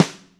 gretsch damped sn f.wav